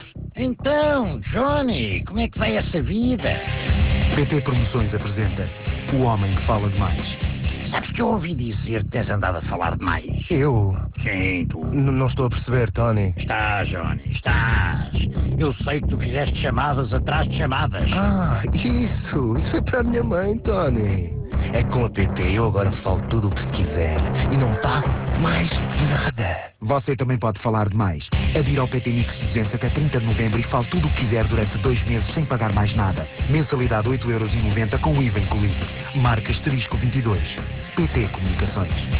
No passado dia 27 de Outubro estreou em rádio uma nova campanha da PT Comunicações para o novo Plano PT Mix 200.
clique para ouvir o spot) está a passar na RFM e RC tendo desde a estreia do spot até ao dia 11 de Novembro sido emitidos 237 anúncios com um investimento de 70.288 euros, a preço tabela.